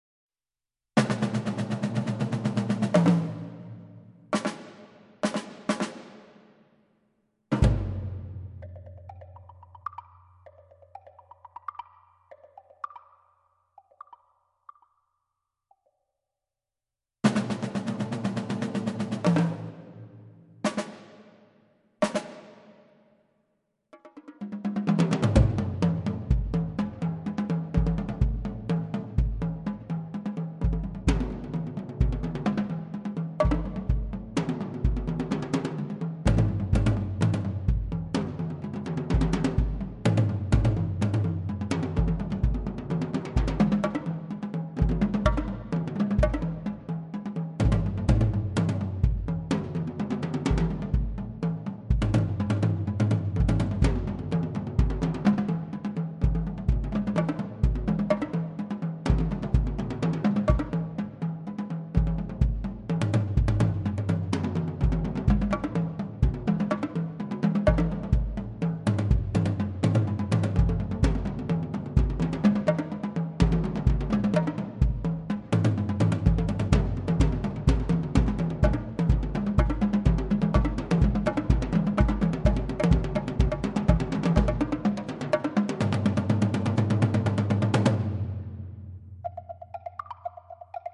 small multiple percussion set-ups.